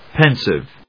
音節pen・sive 発音記号・読み方
/pénsɪv(米国英語), ˈpɛn.sɪv(英国英語)/